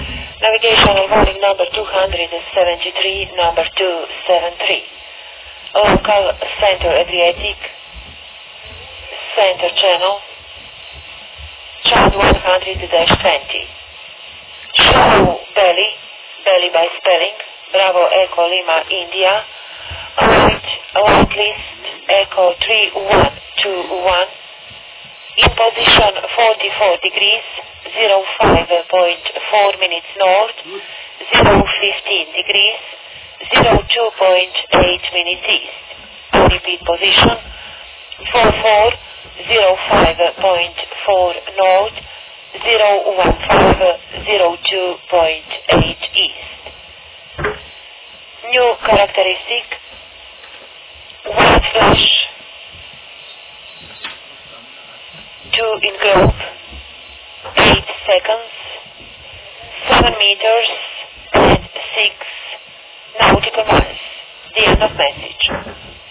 Die Küstenfunkstelle Split Radio sendet derzeit um 05:45, 12:45, 19:45 und 00:45 UTC.
Folgende Hörprobe bezieht sich auf die Meldung #273 aus obiger handschriftlicher Liste, gleich darunter der genaue Wortlaut.